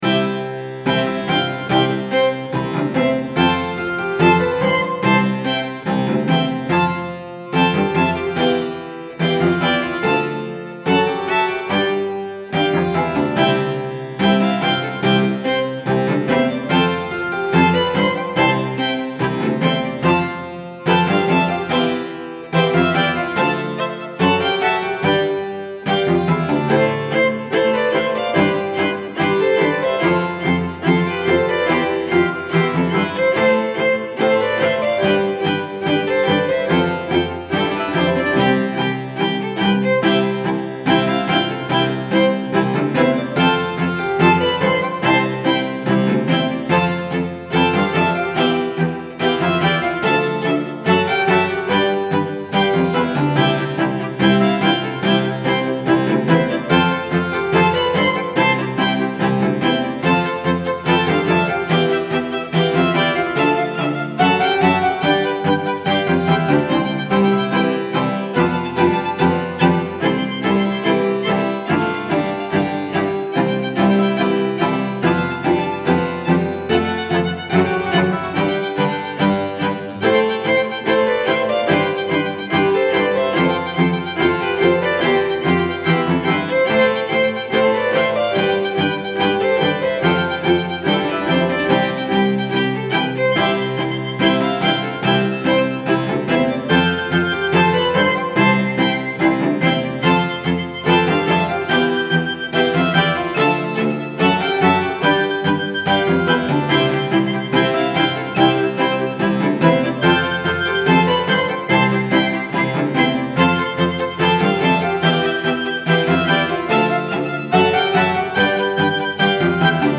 Style Style Classical
Mood Mood Bright, Uplifting
Featured Featured Cello, Harpsichord, Strings +1 more
BPM BPM 72
Quite uplifting, and traditional sounding.